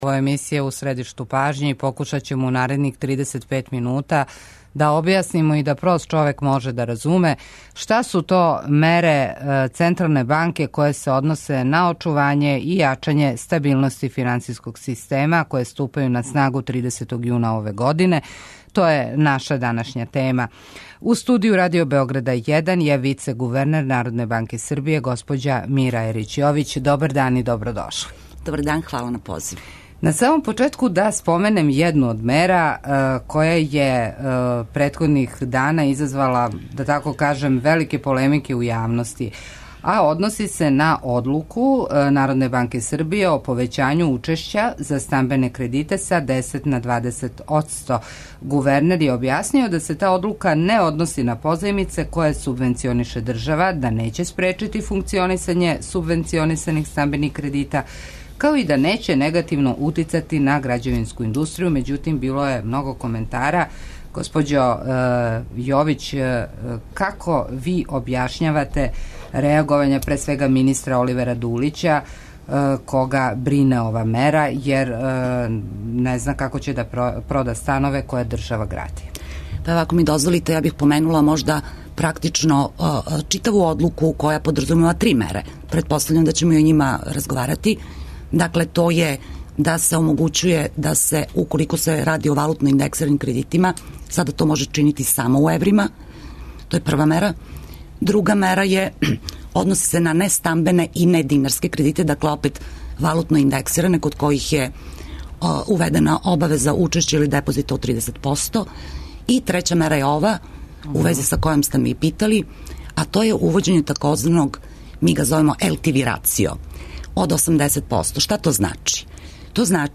Гошћа емисије је Мира Ерић Јовић, вицегувернер Народне банке Србије.